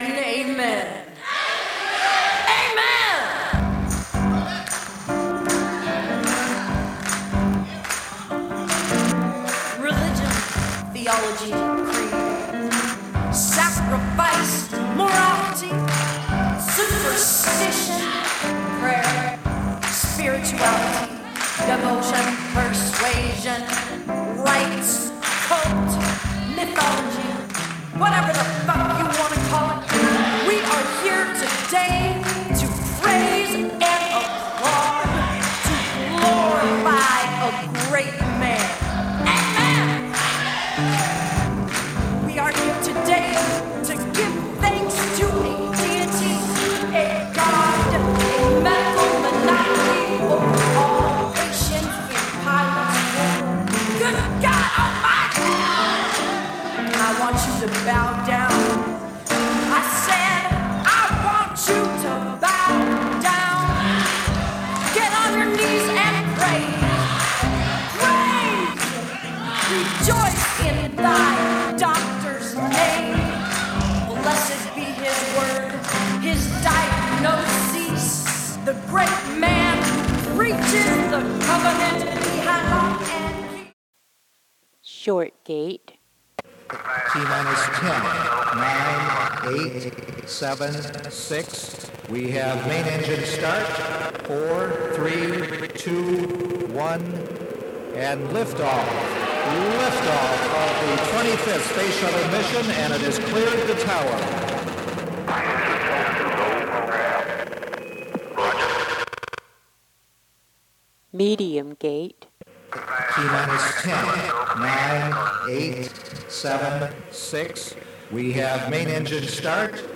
SDS_VCO_LOFI8andTG.mp3
The gate length changing will drastically change the delay until it completes the buffer. If you can, pan over to the right channel to remove the original audio.